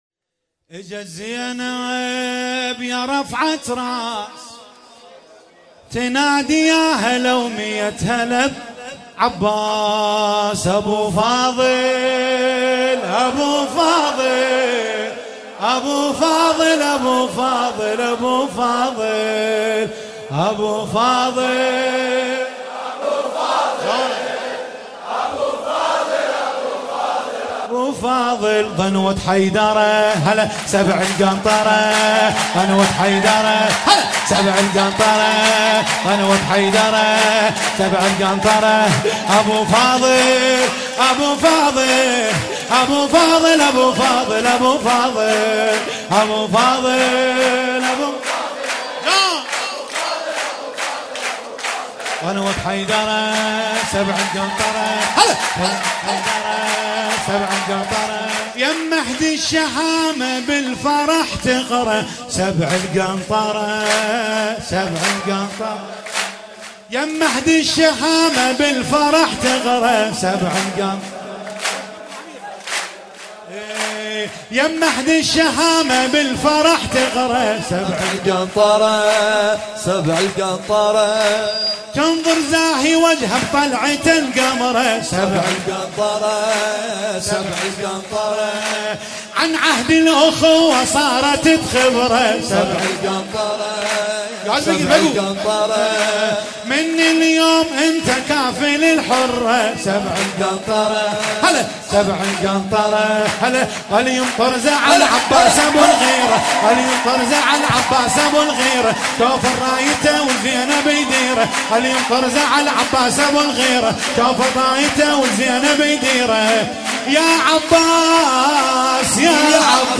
مولودی عربی